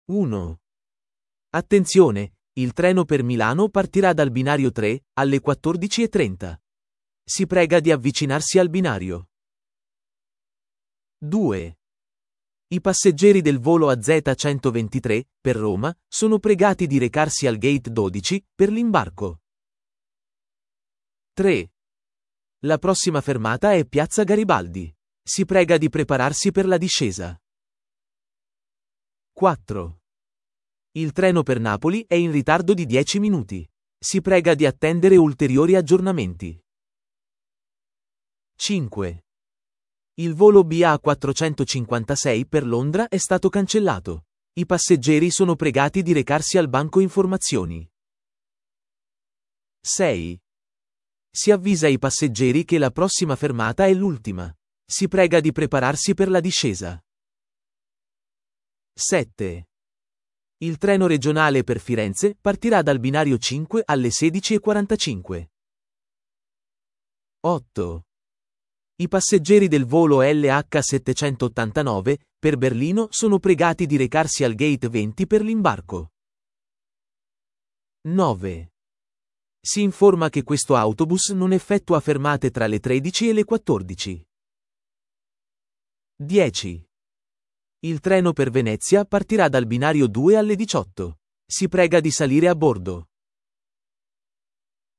Annunci pubblici e messaggi